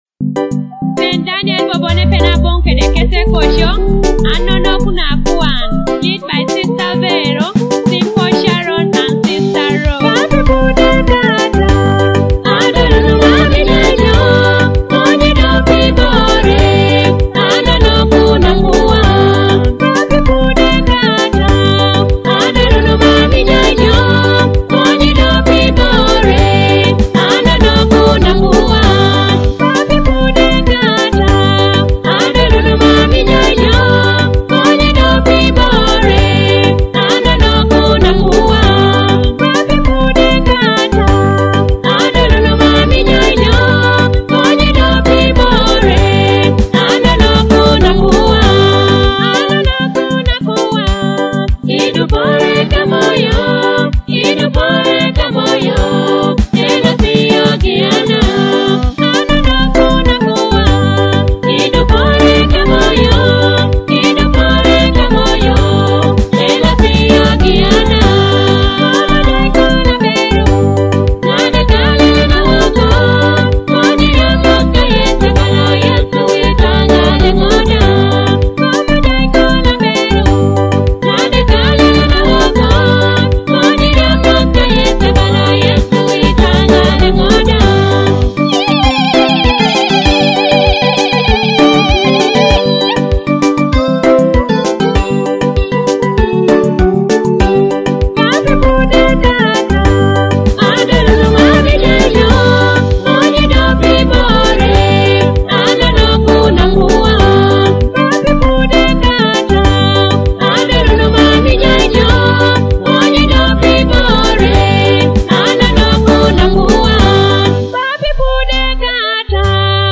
feel the uplifting spirit of this must-have anthem